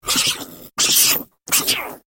На этой странице собраны звуки, которые издают крысы: от тихого писка до активного шуршания.
Звук атаки дикой крысы в ярости